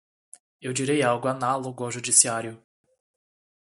Pronounced as (IPA)
/aˈna.lo.ɡu/